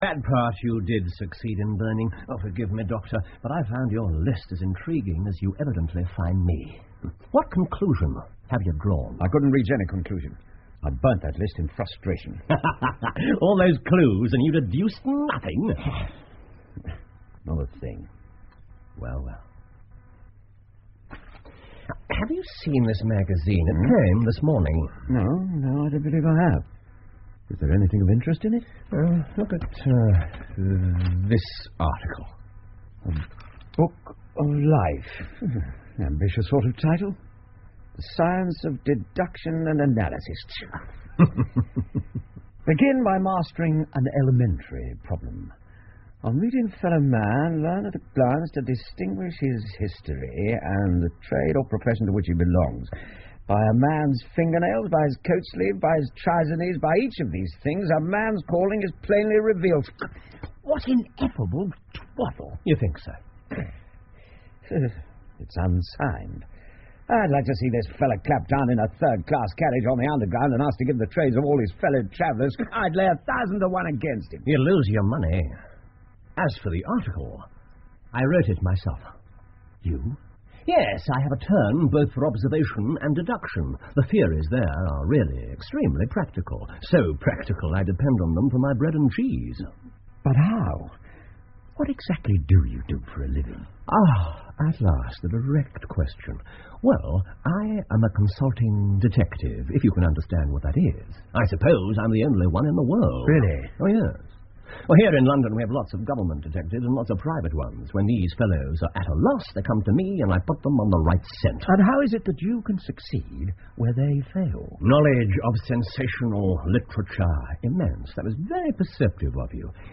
福尔摩斯广播剧 A Study In Scarlet 血字的研究 5 听力文件下载—在线英语听力室